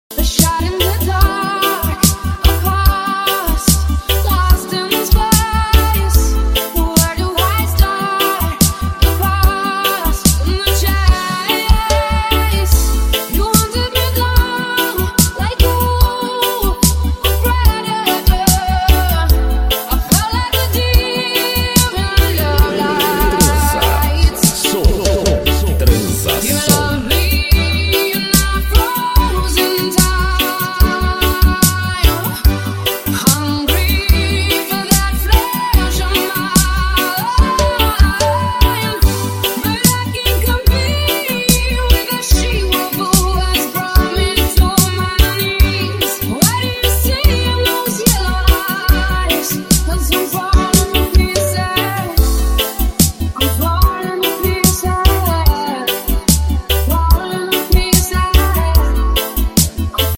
Ascendant Audio Havoc subwoofers. 1500rms sound effects free download